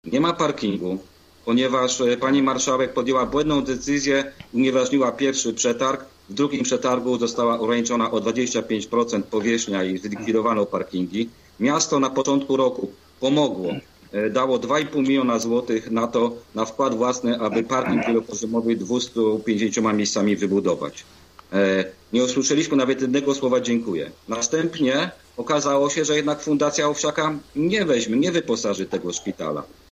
Dziś dyskutowali na ten temat goście audycji „Sobota po 9”.